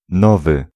Ääntäminen
Ääntäminen Tuntematon aksentti: IPA: [ˈnɔvɨ] Haettu sana löytyi näillä lähdekielillä: puola Käännös Ääninäyte Adjektiivit 1. new UK US US 2. novel US Suku: m .